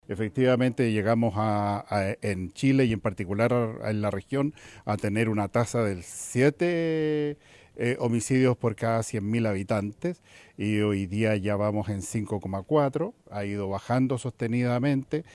Pese a la seguidilla de casos de homicidios, el delegado presidencial Yanino Riquelme manifestó que las políticas públicas en materia de seguridad han tenido un impacto en las cifras por estos delitos en la región.